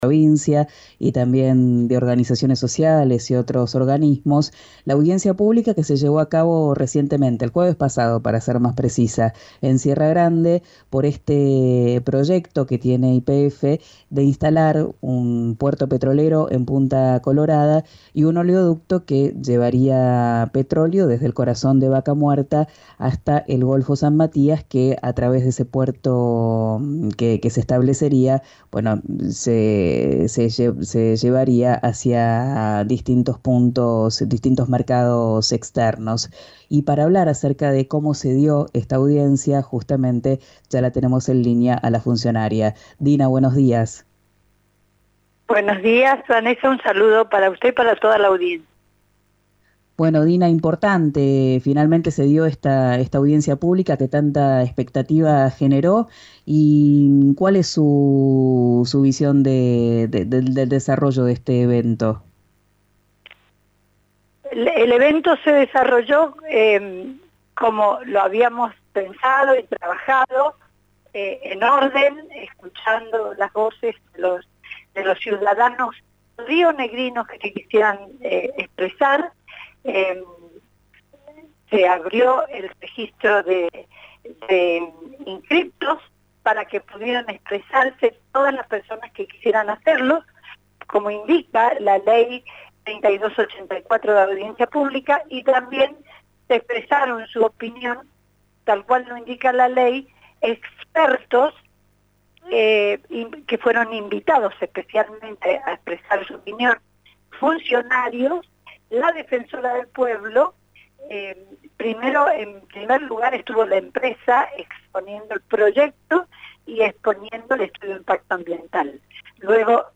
En primer lugar, estuvo la empresa exponiendo el proyecto y exponiendo el Estudio de Impacto Ambiental», marcó Migani en diálogo con «Arranquemos» de RÍO NEGRO RADIO.